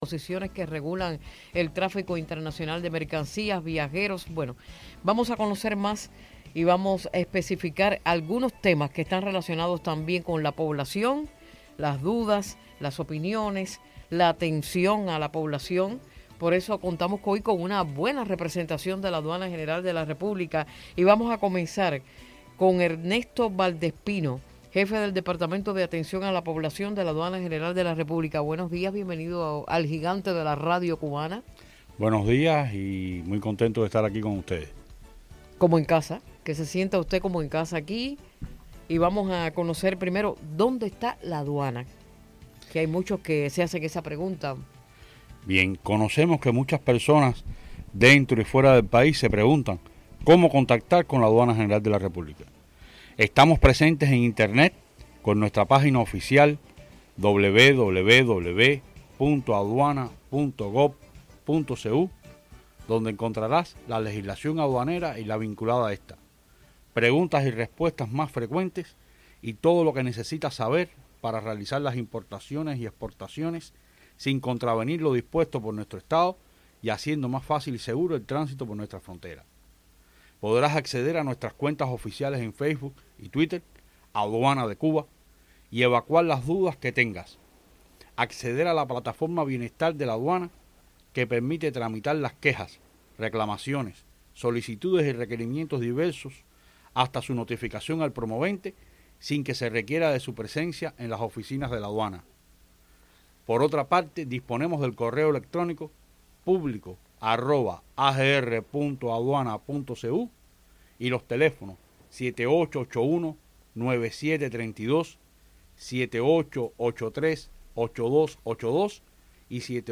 To get closer to the traveler, Cuban Customs arrives at Radio Rebelde.